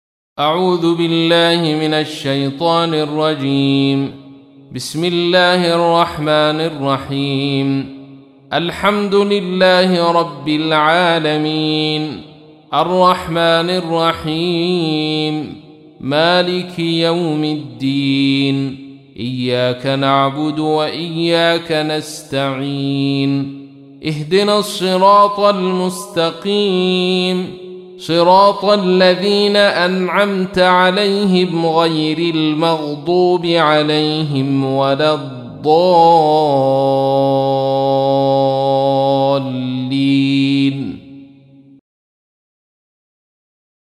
تحميل : 1. سورة الفاتحة / القارئ عبد الرشيد صوفي / القرآن الكريم / موقع يا حسين